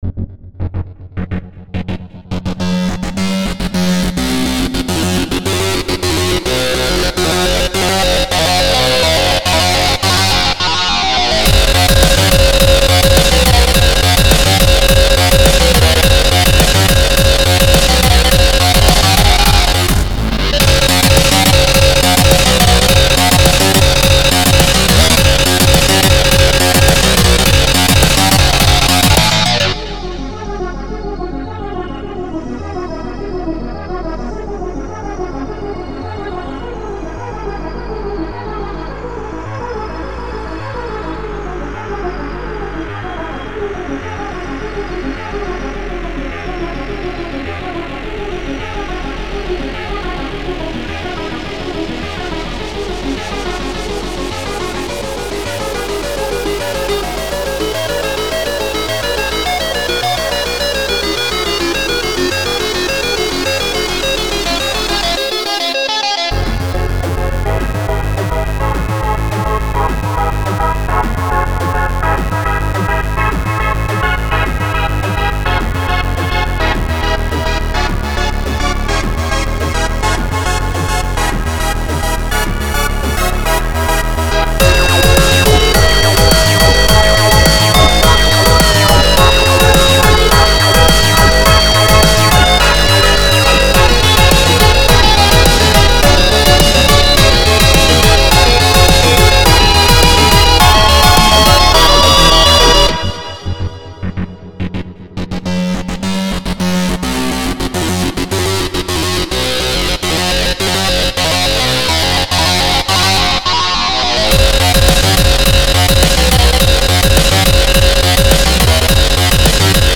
Very powerful techno!
Techno